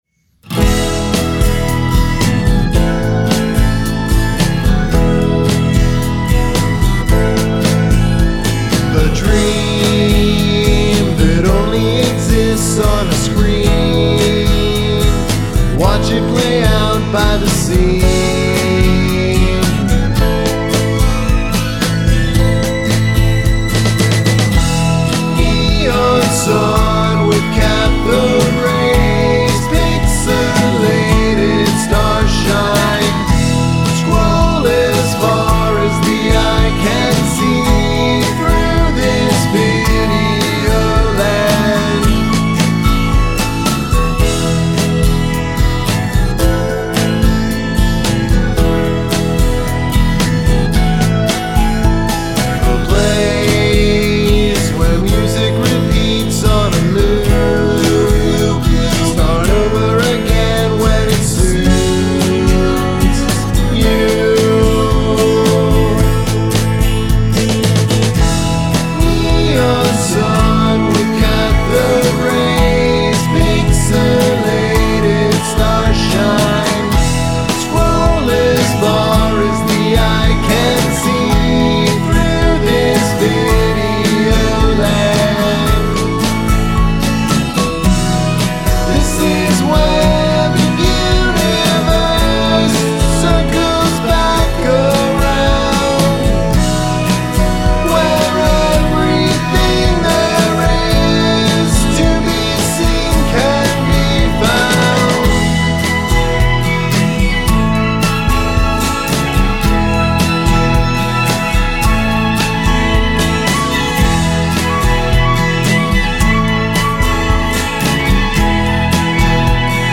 Must include a classical music sample